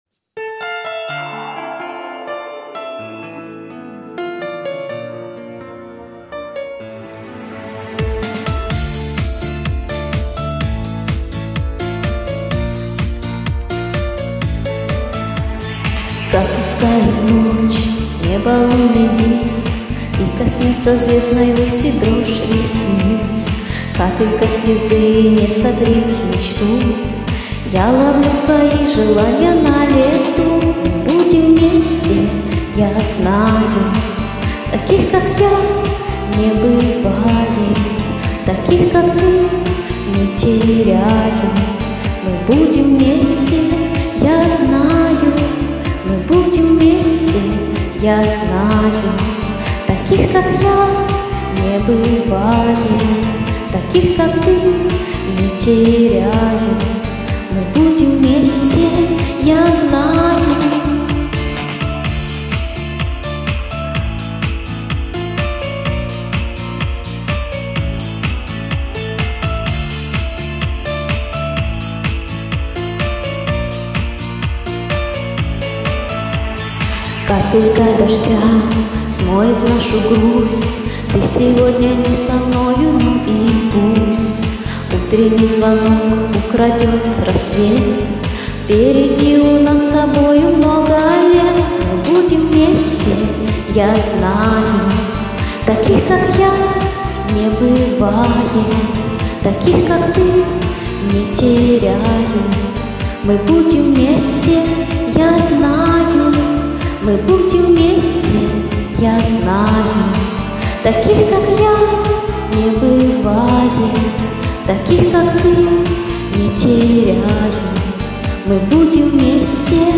mp3 0:00 Співаю_караоке_кому_цікаво____ Скачать